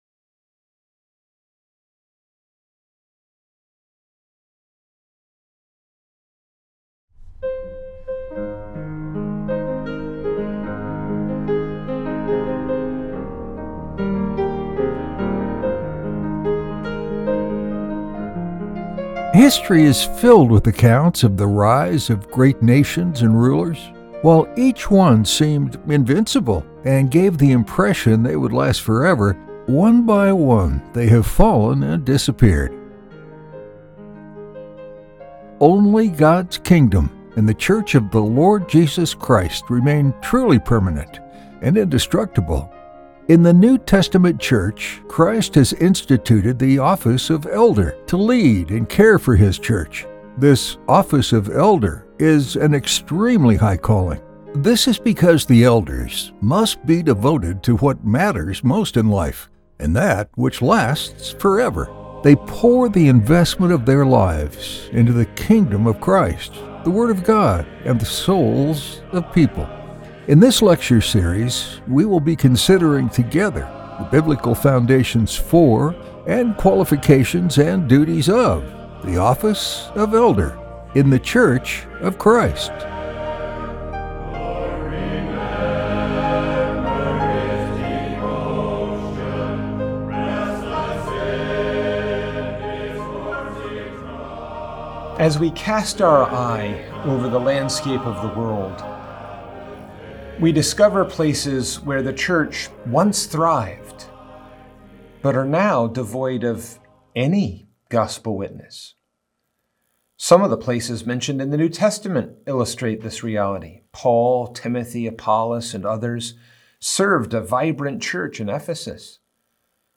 In his letters to the churches in Asia, in the book of Revelation, Christ threatens to remove their light unless they hold to and protect right doctrine. In this lecture, we learn that one of the functions of Ruling Elders in the church is to hold to, protect, and teach right doctrine in the church.